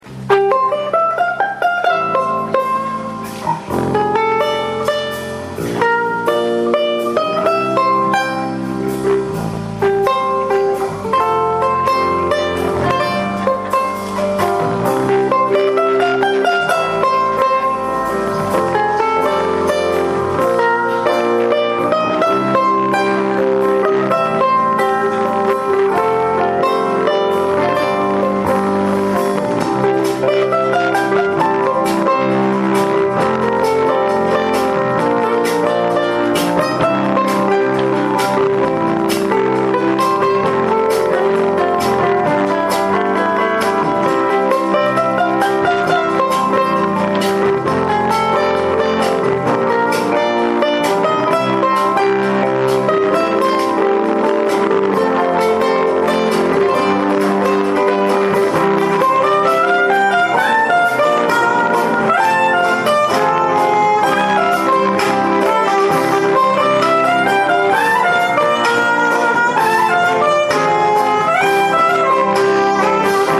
guitarra y percusión
Saxos
Violín
Teclados
Batería y percusión
Bajo